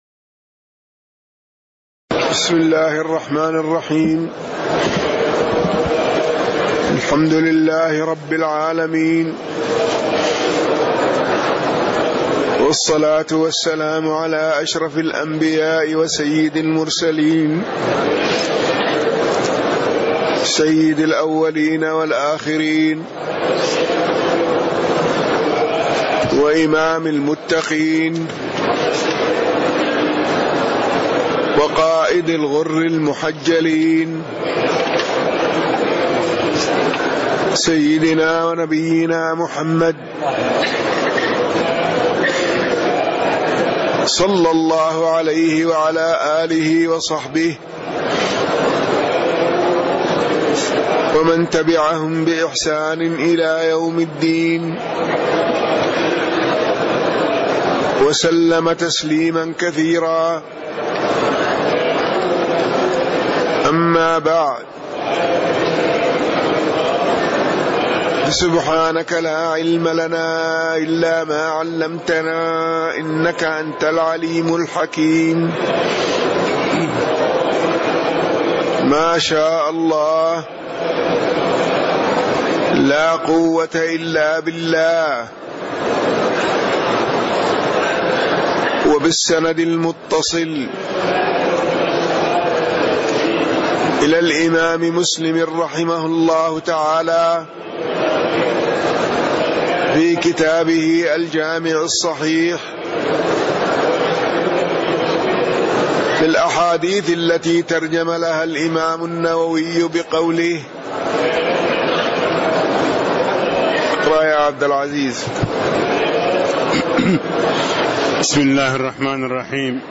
تاريخ النشر ٢١ ذو الحجة ١٤٣٦ هـ المكان: المسجد النبوي الشيخ